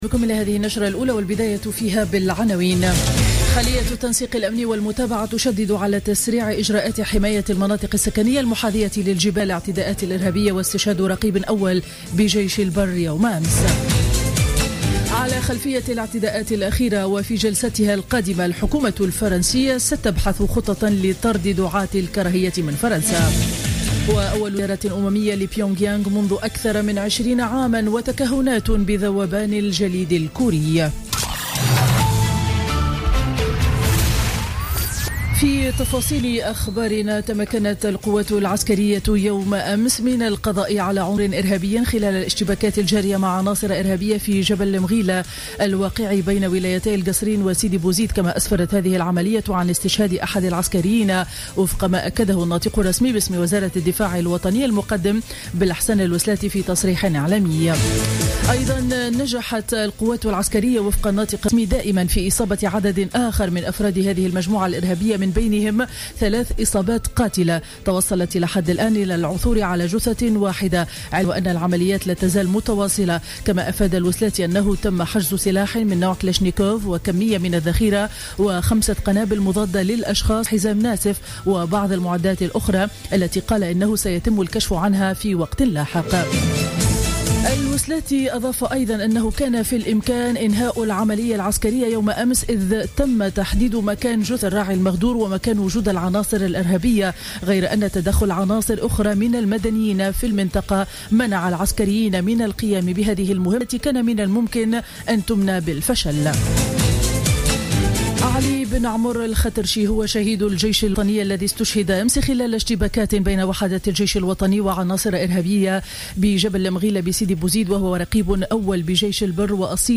Journal Info 07h00 du lundi 16 novembre 2015